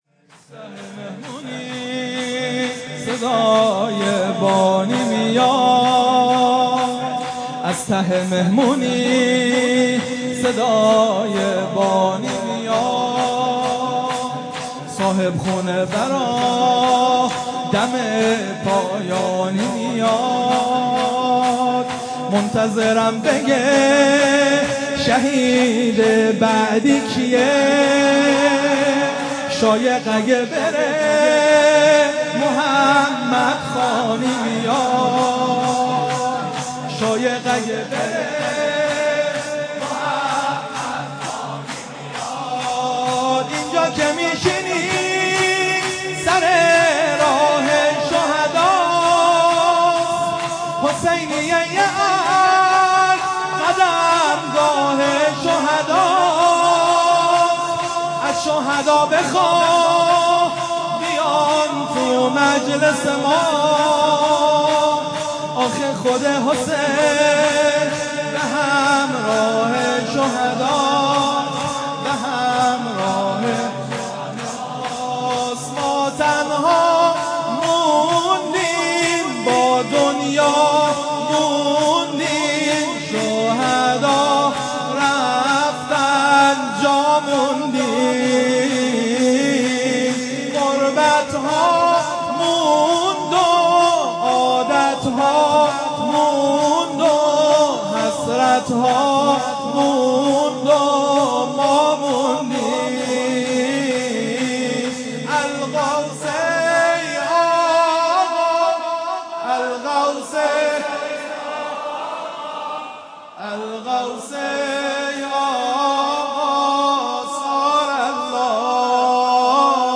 شور | از ته مهمونی
مداحی
مراسم شب قدر 97/03/17